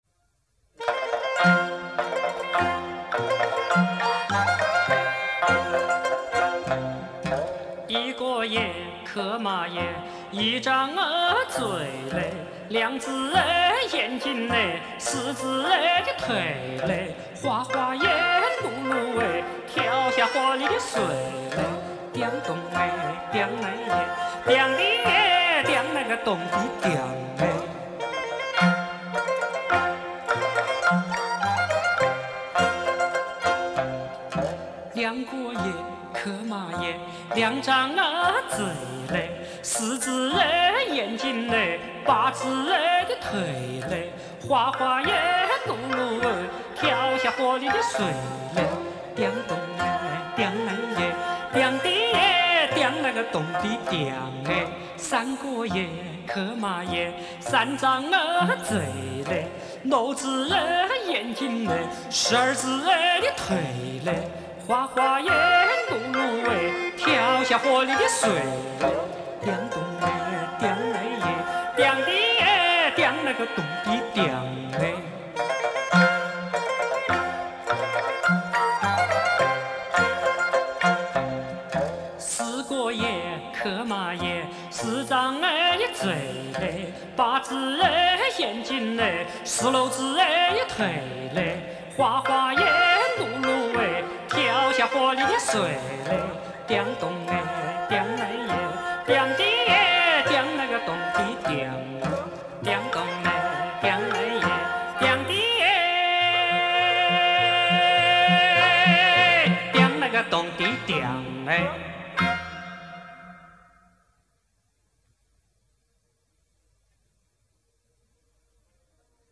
湖北潜江民歌